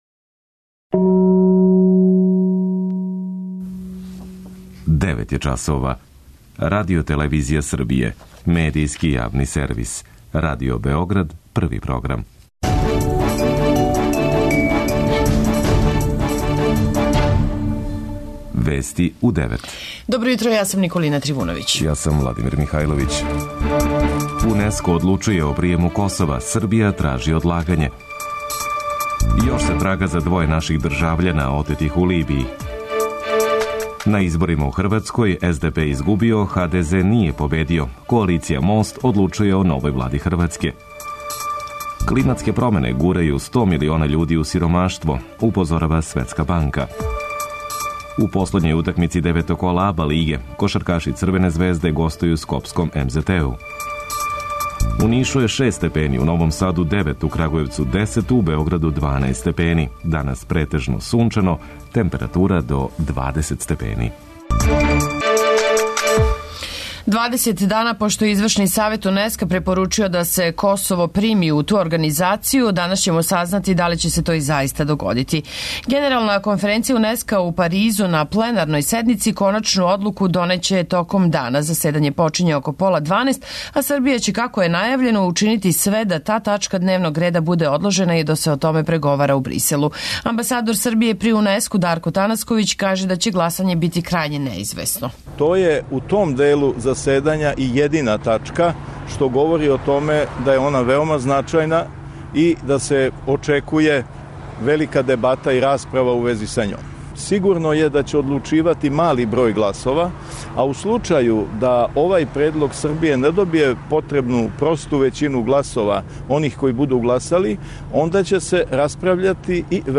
преузми : 10.44 MB Вести у 9 Autor: разни аутори Преглед најважнијиx информација из земље из света.